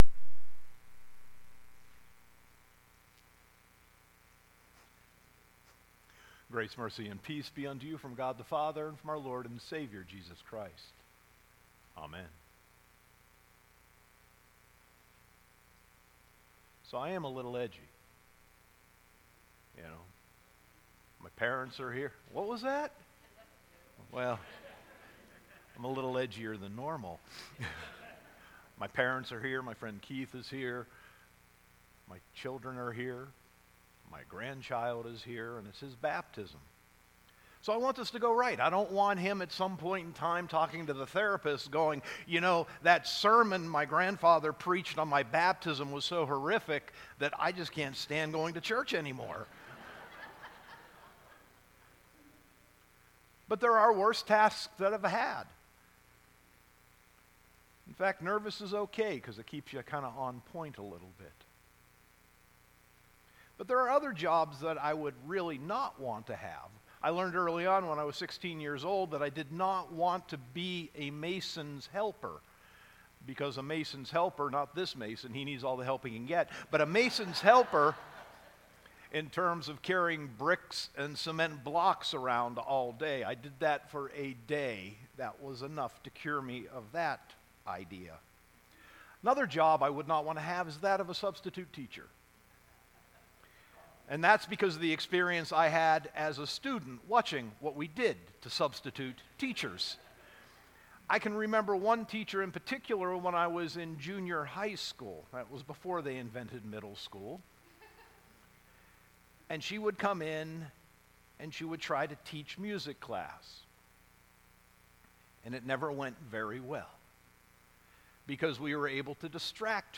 Sermon 9.30.2018